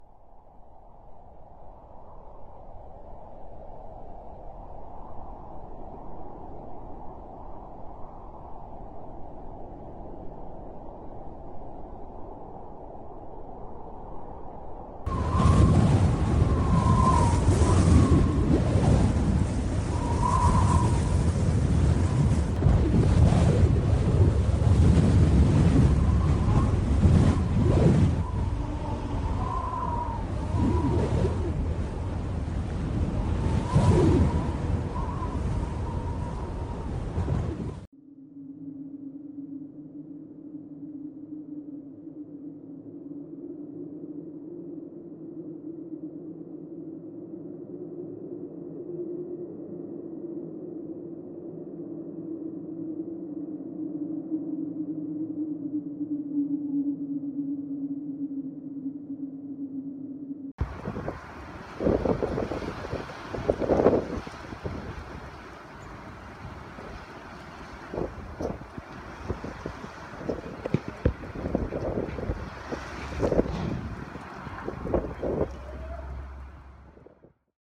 Audio-vent-1.mp3